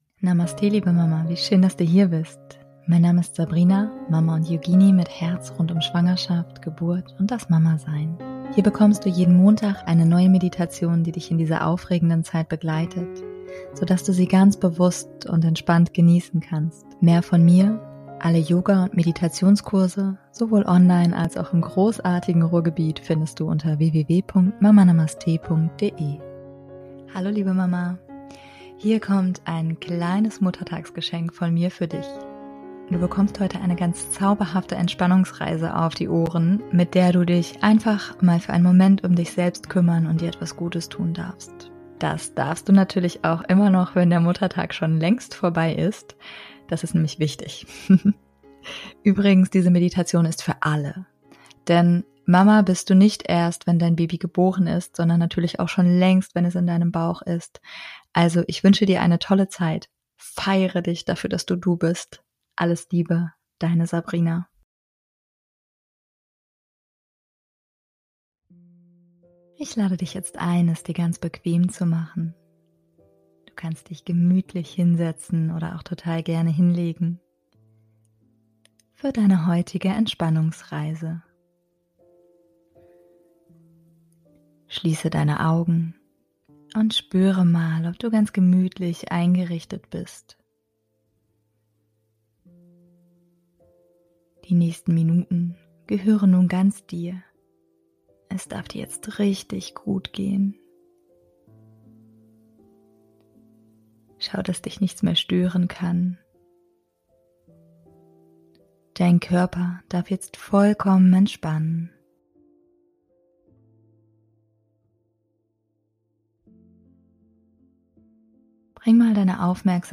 Beschreibung vor 2 Jahren Bevor wir starten kommt hier eine kurze Werbung in eigener Sache.
Du bekommst heute eine ganz zauberhafte Entspannungsreise auf die Ohren, mit der du dich einfach mal für einen Moment um dich selbst kümmern und dir etwas Gutes tun darfst.